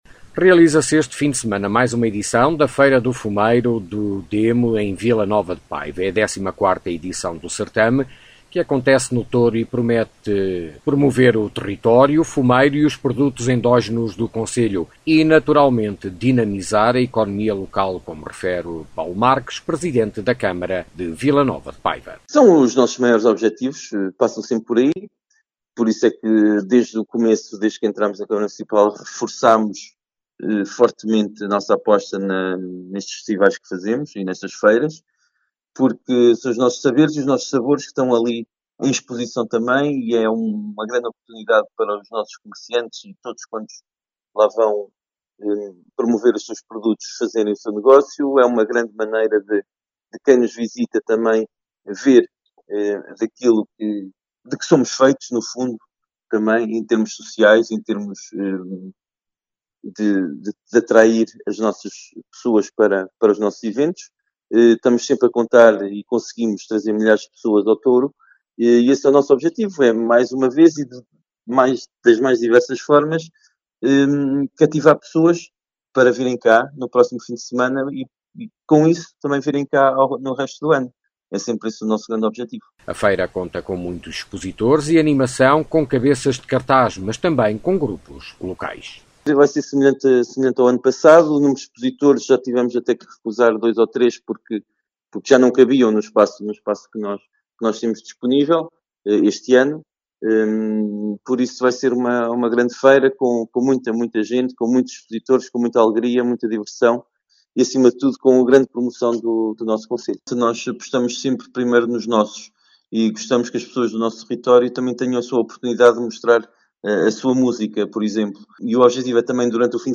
O evento, que acontece no Touro, tem como objetivo destacar o fumeiro e os produtos endógenos do concelho, impulsionando a economia regional, como explica Paulo Marques, presidente da Câmara Municipal de Vila Nova de Paiva.